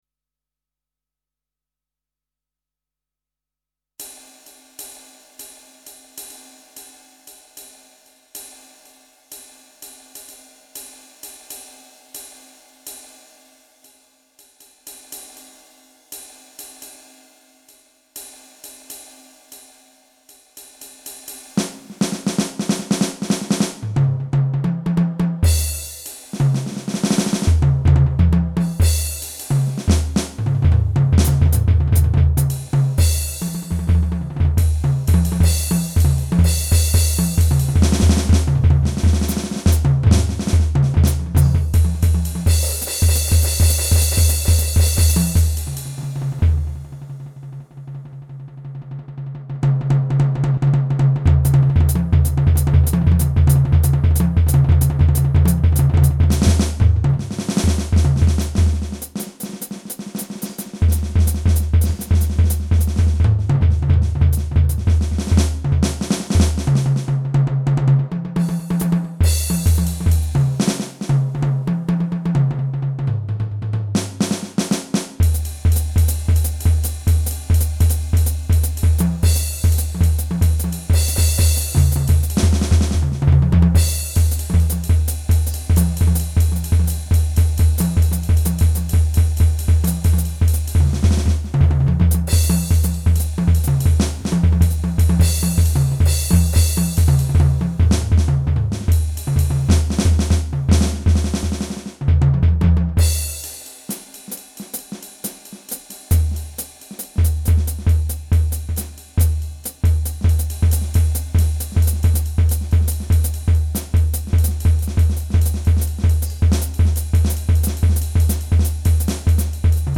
Essais percussifs